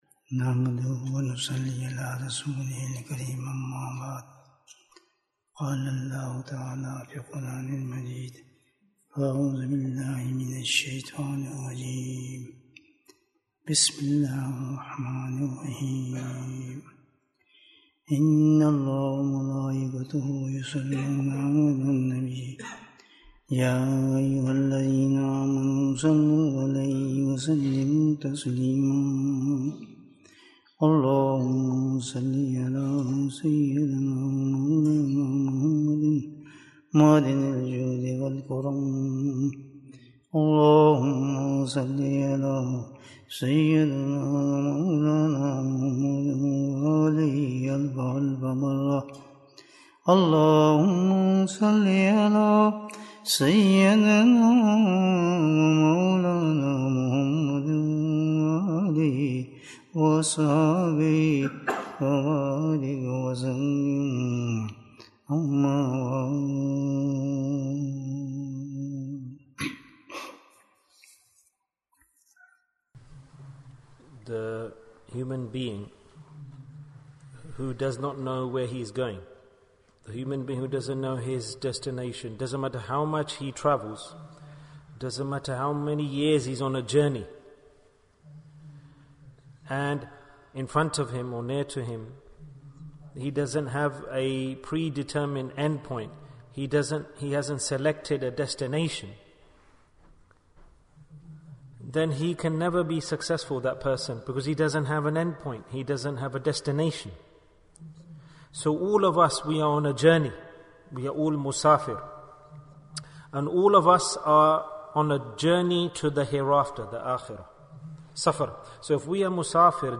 Obstacles in the Path of Allah & Their Cure Bayan, 41 minutes28th July, 2022